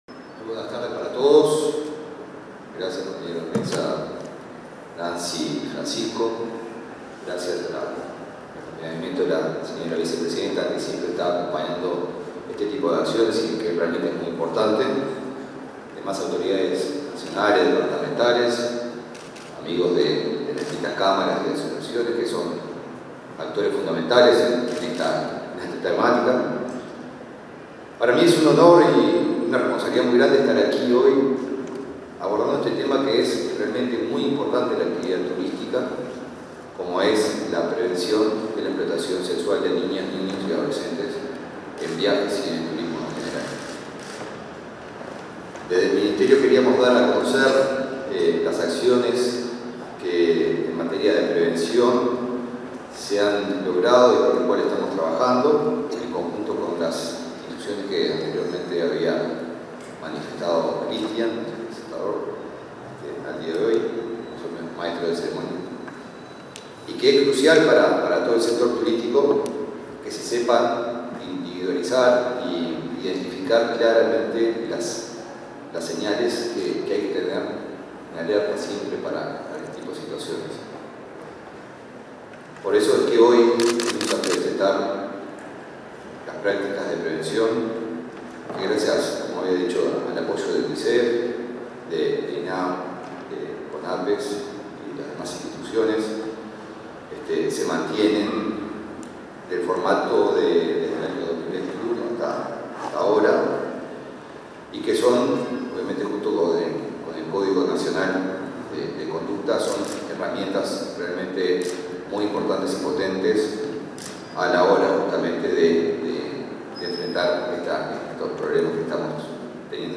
Palabras del ministro de Turismo, Eduardo Sanguinetti, y la titular de Conapees, Nancy Lema